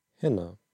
Utspraak von hinner (Audio)
Utspraak op Platt: /hɪnɐ/